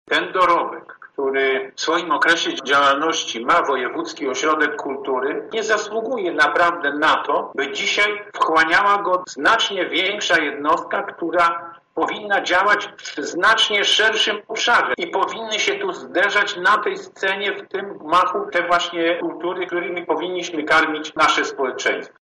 Te dwie organizacje są o zupełnie innych zakresach działalności – mówi radny Sławomir Sosnowski z Polskiego Stronnictwa Ludowego: